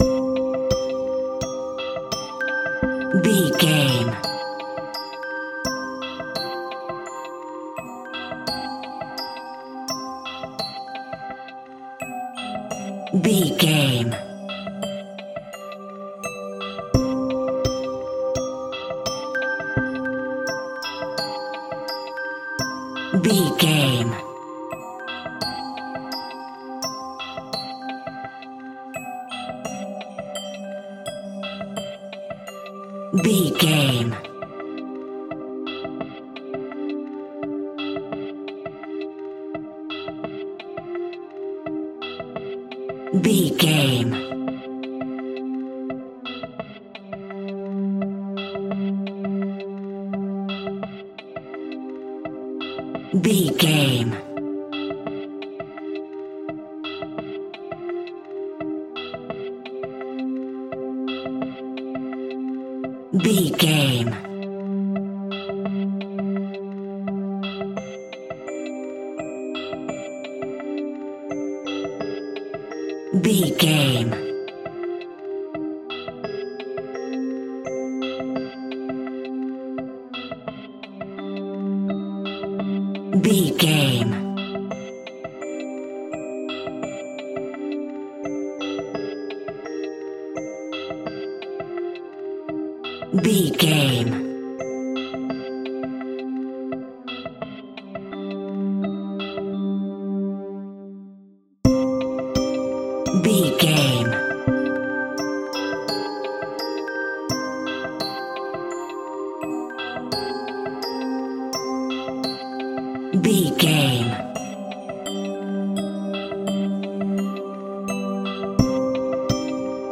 Music for Horror.
Aeolian/Minor
scary
ominous
dark
suspense
eerie
synthesizer
percussion
Synth Pads
atmospheres